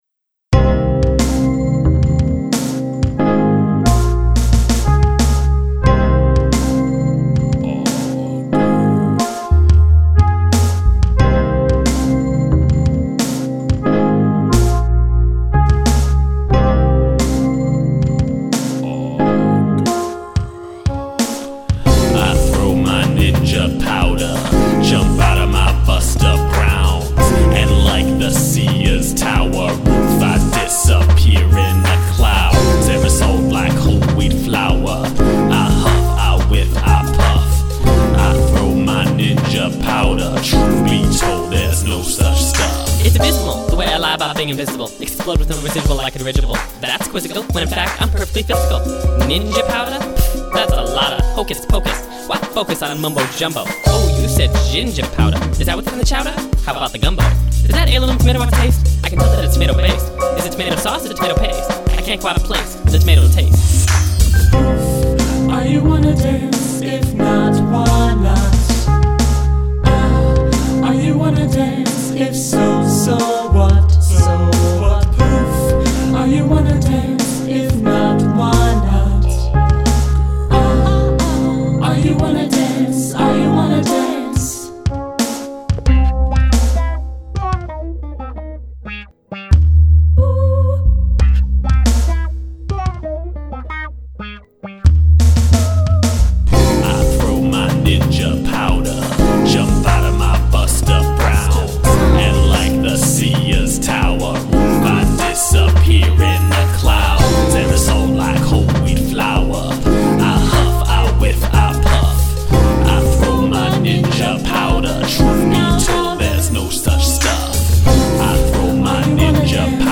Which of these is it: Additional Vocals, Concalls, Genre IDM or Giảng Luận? Additional Vocals